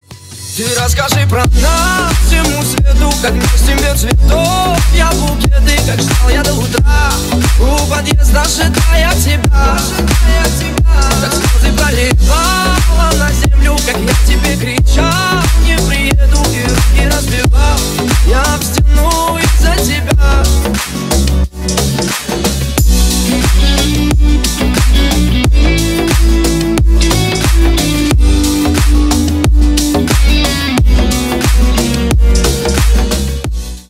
Ремикс
клубные